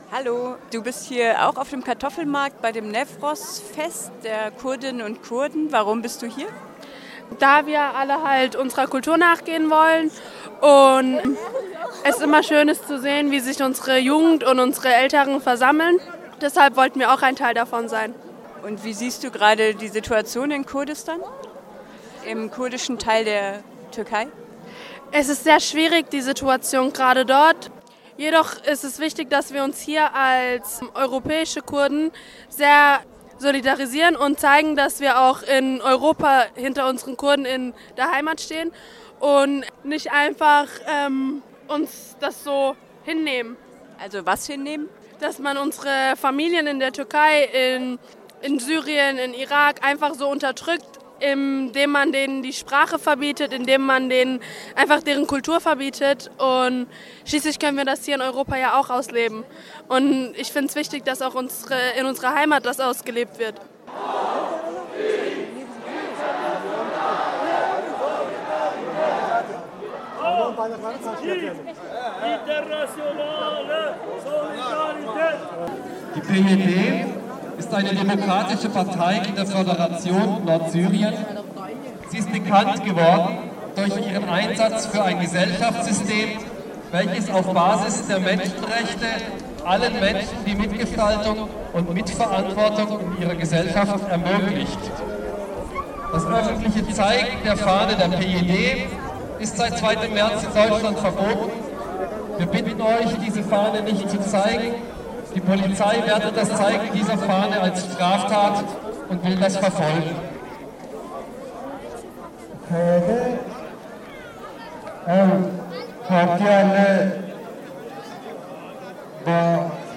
Hier hört ihr einige Eindrücke des Newrozfestes, das in Freiburg aus einer Kundgebung am Kartoffelmarkt mit anschliessender Demonstration durch die Innenstadt bestand.